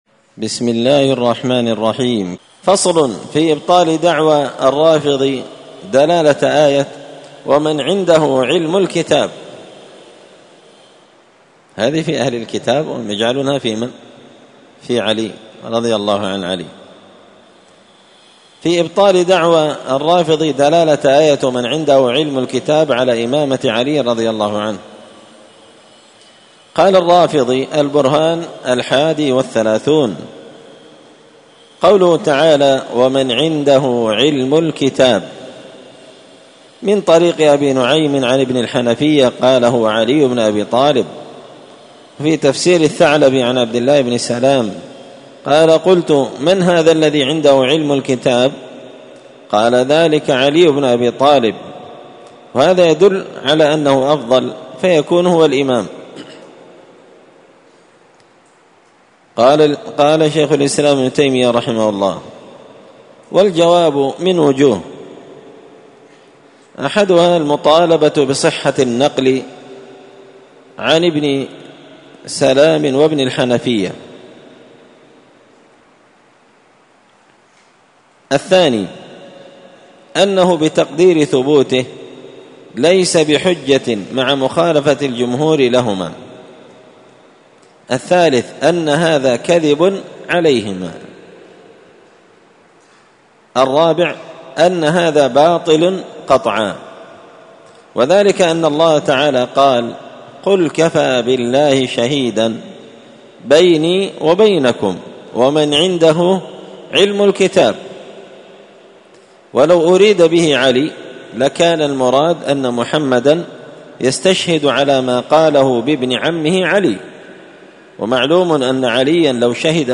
الأربعاء 14 صفر 1445 هــــ | الدروس، دروس الردود، مختصر منهاج السنة النبوية لشيخ الإسلام ابن تيمية | شارك بتعليقك | 40 المشاهدات
مسجد الفرقان قشن_المهرة_اليمن